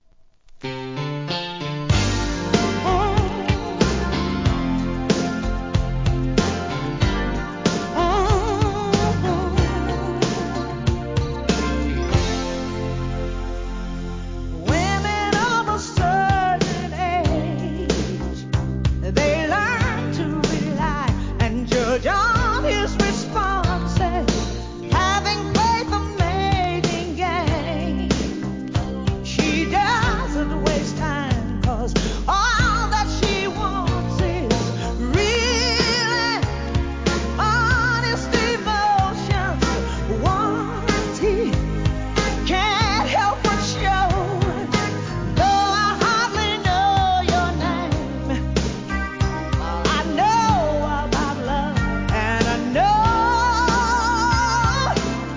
¥ 550 税込 関連カテゴリ SOUL/FUNK/etc...